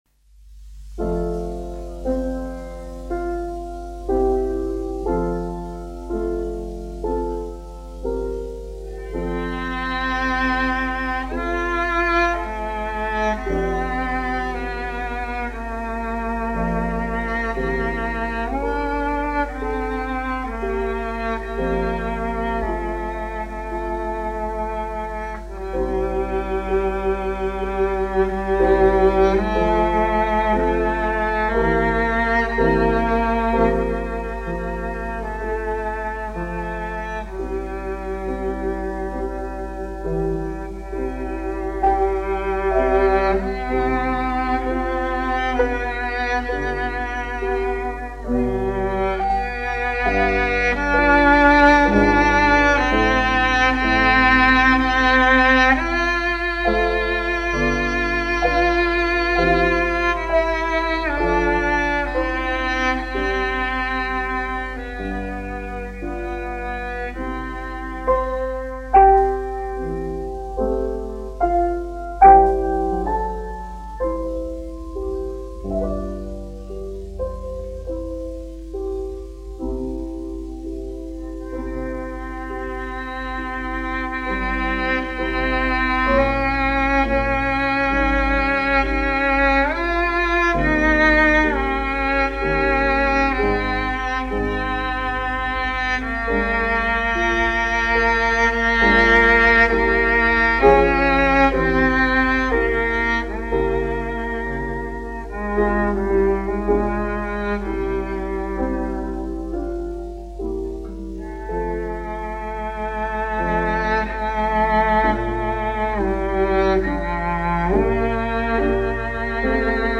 1 skpl. : analogs, 78 apgr/min, mono ; 25 cm
Čella un klavieru mūzika, aranžējumi
Latvijas vēsturiskie šellaka skaņuplašu ieraksti (Kolekcija)